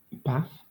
Ääntäminen
Ääntäminen Southern England Tuntematon aksentti: IPA : /bæf/ Haettu sana löytyi näillä lähdekielillä: englanti Käännöksiä ei löytynyt valitulle kohdekielelle.